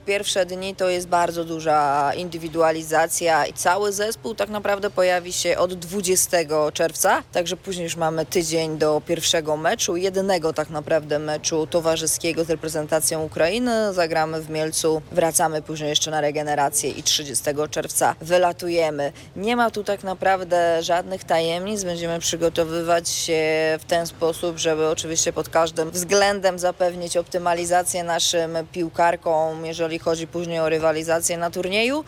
O szczegółach przygotowań opowiada selekcjonerka Nina Patalon: